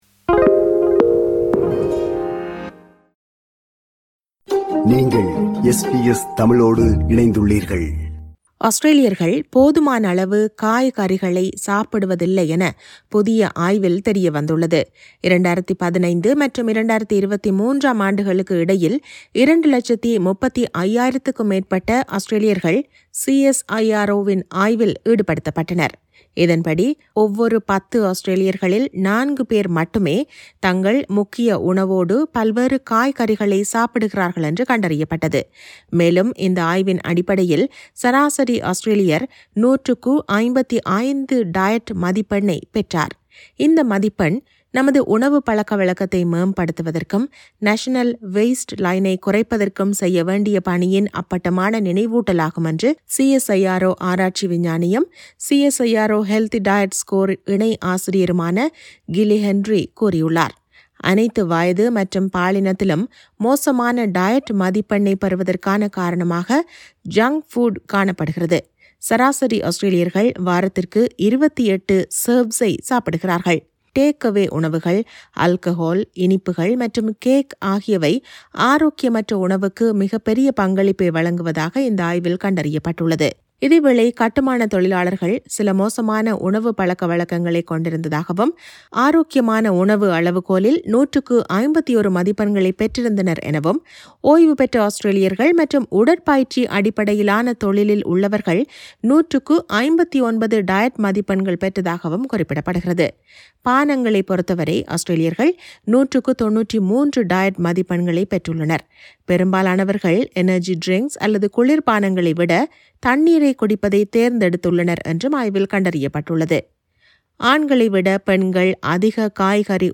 ஆஸ்திரேலியர்கள் போதுமானளவு காய்கறிகளை சாப்பிடுவதில்லை என புதிய ஆய்வில் தெரியவந்துள்ளது. இதுகுறித்த செய்தி விவரணத்தை முன்வைக்கிறார்